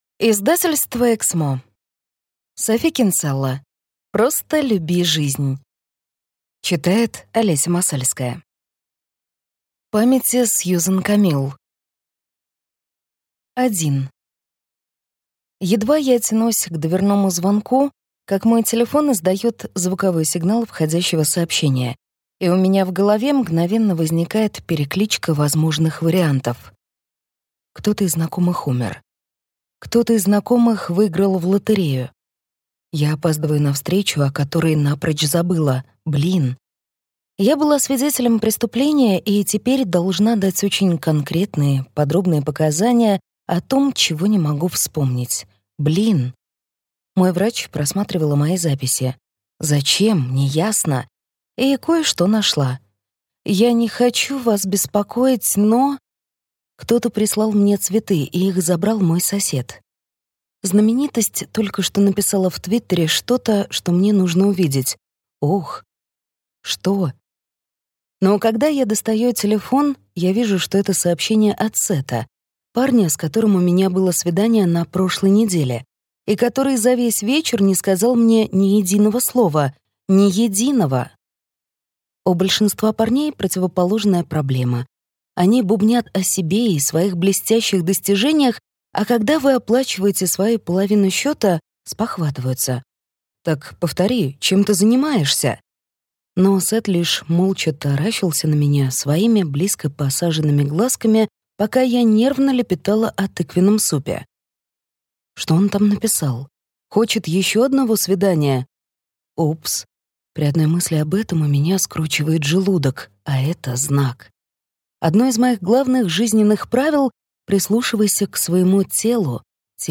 Аудиокнига Просто люби жизнь | Библиотека аудиокниг
Прослушать и бесплатно скачать фрагмент аудиокниги